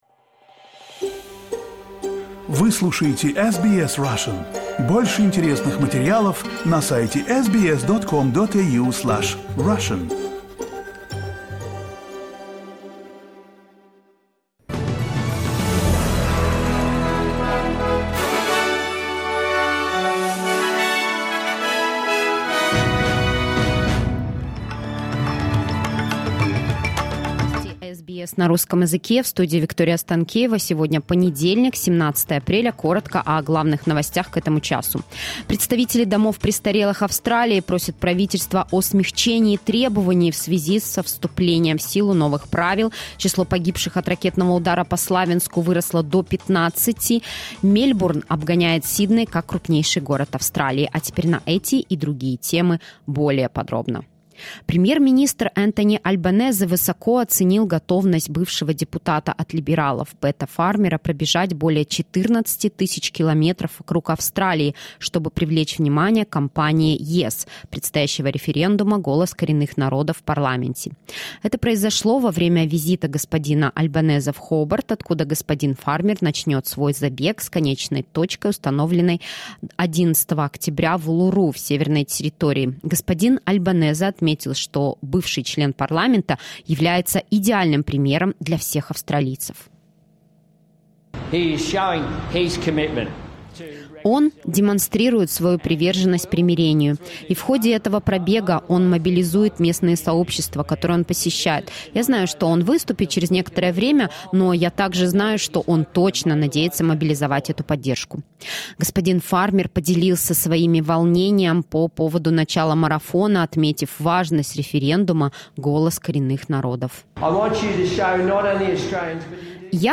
SBS news in Russian — 17.04.2023